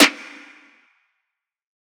The following examples use the following three Roland TR808m drum machine sound samples from FreeSound:
snare drum